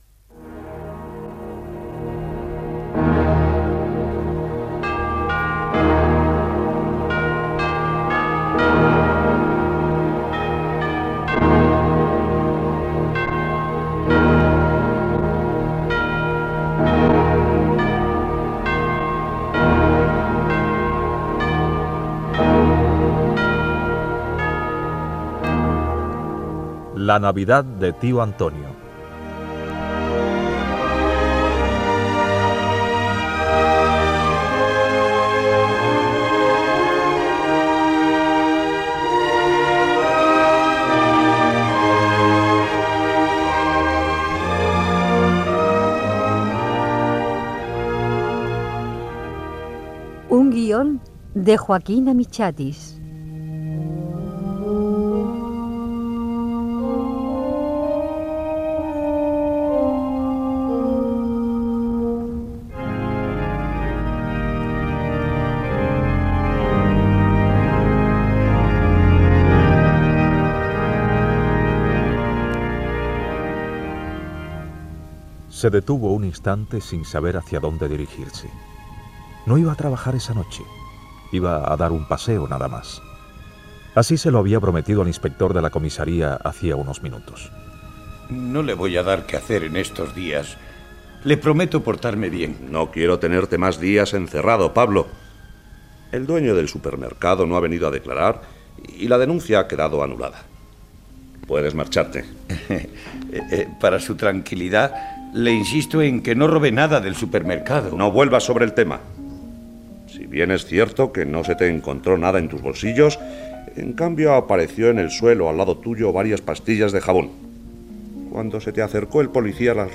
Careta del programa i fragment de l'obra escrita per Joaquín Amichatis Gènere radiofònic Ficció